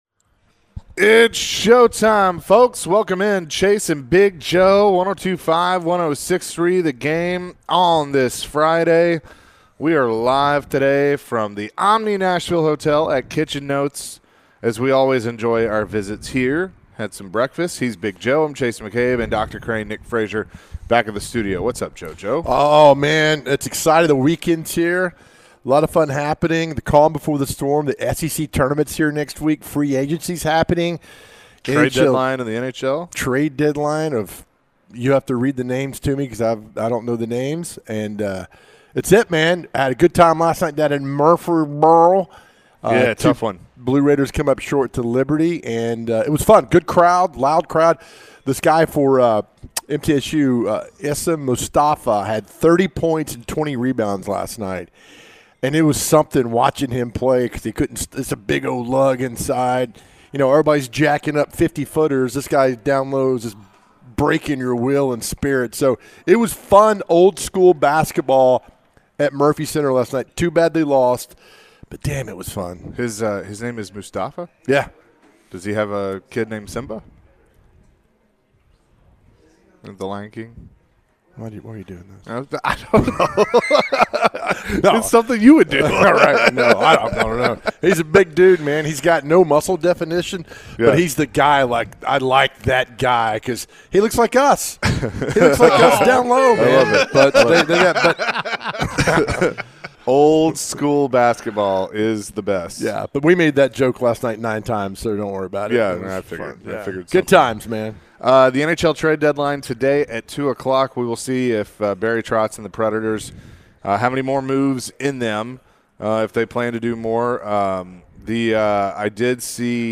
Today is middle name day, what is your middle name? The guys answered some calls and texts about middle names.